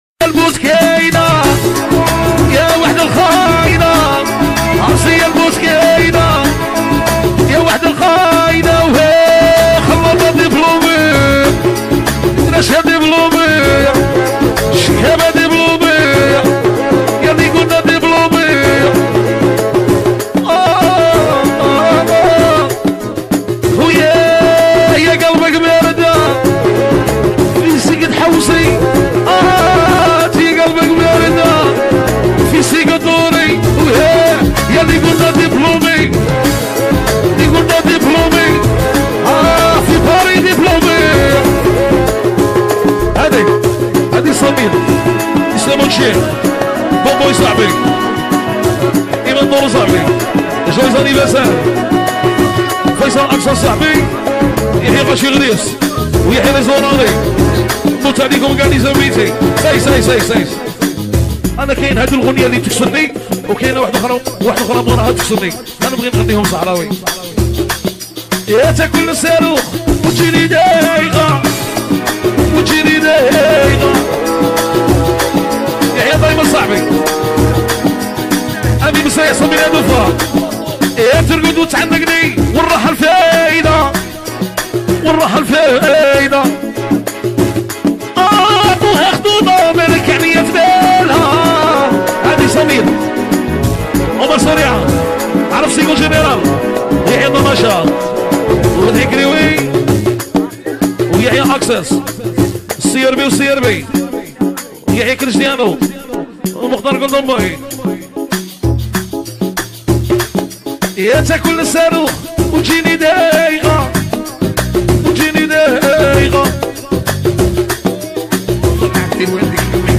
" اغاني راي جزائري "